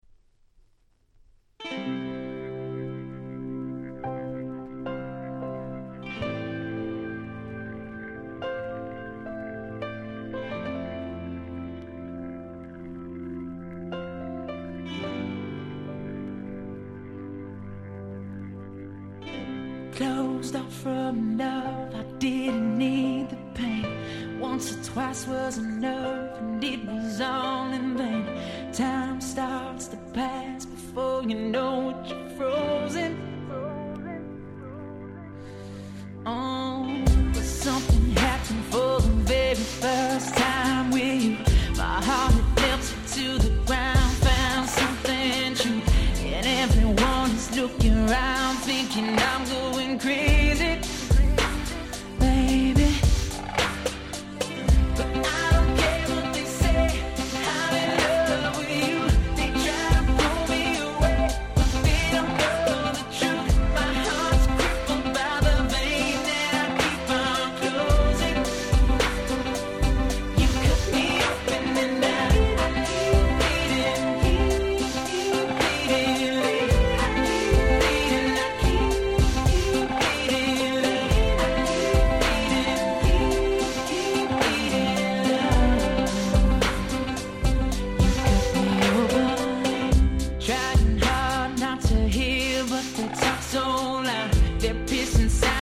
08' Nice R&B !!